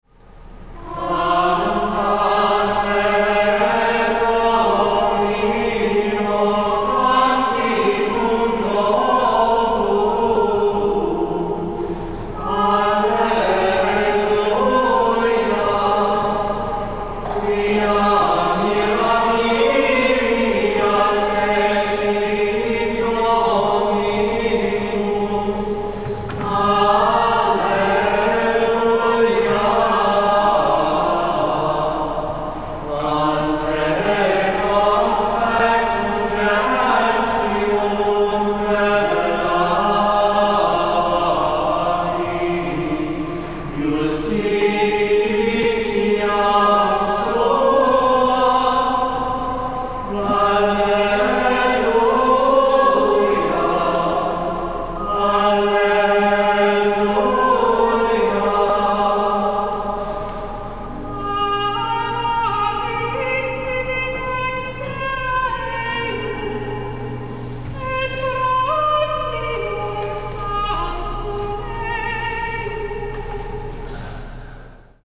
Here are audio clips of some Gregorian chant (excerpts in each case) from the abbey, last Sunday’s Fifth Sunday of Easter, with some musings.
Cantate Domino is the proper introit (entrance chant) of the day. We do the introit as a prelude, and start a few minutes before the hour so we can sing several verses and antiphons, since we took the time to learn the thing.